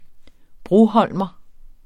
Udtale [ ˈbʁoˌhʌlˀmʌ ]